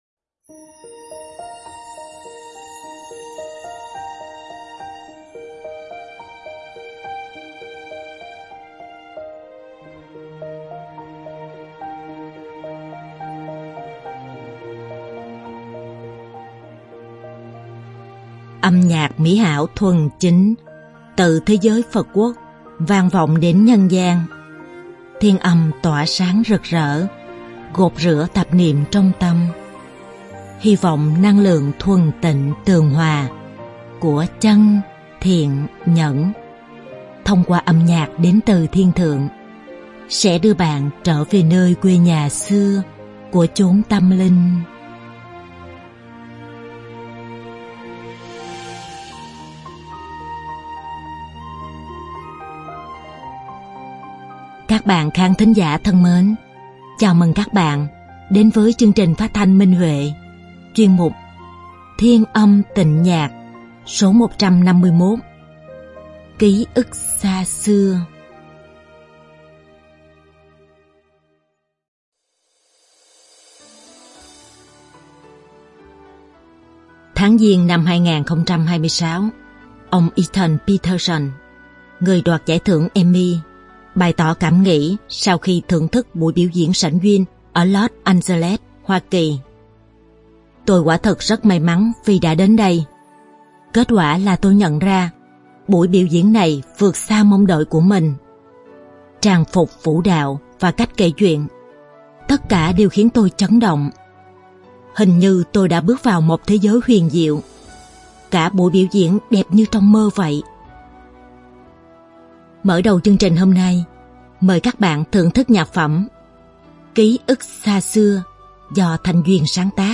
Đơn ca nam
Đơn ca nữ